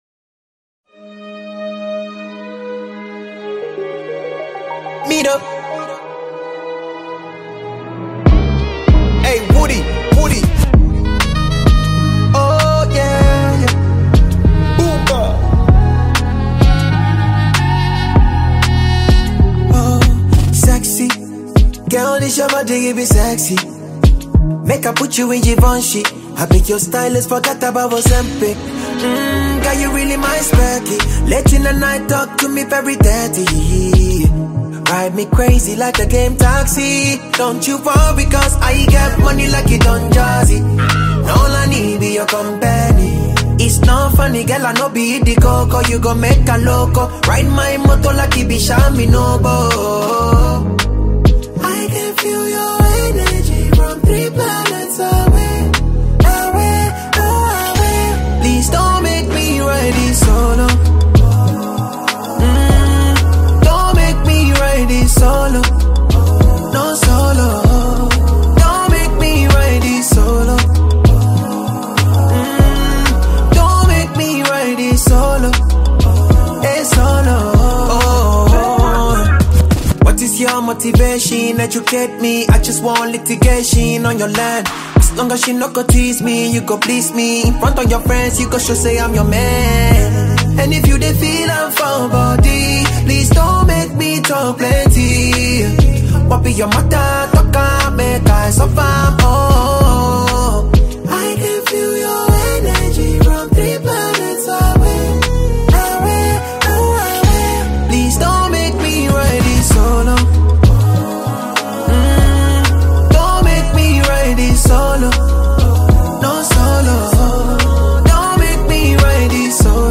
Afrobeat Ghanaian singer and songwriter
afrobeat mixed with a calm highlife genre